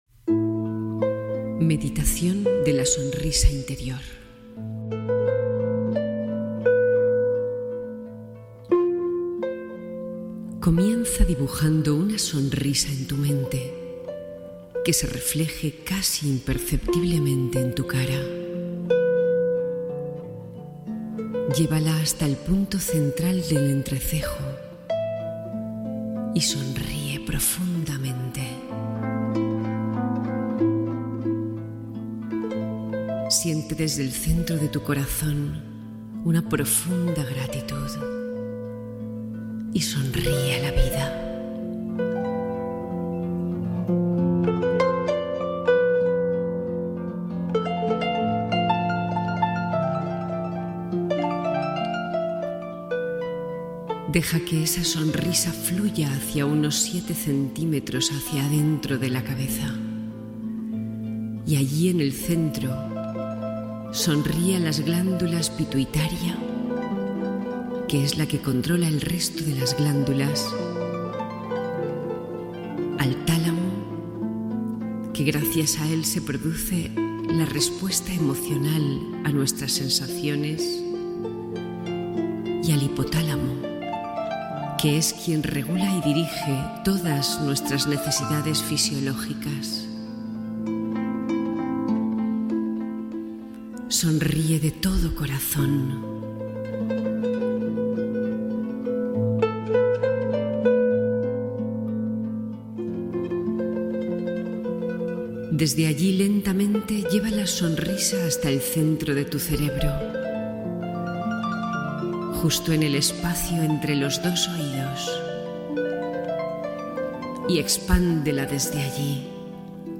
Meditación guiada de la sonrisa interior para liberar estrés y tensiones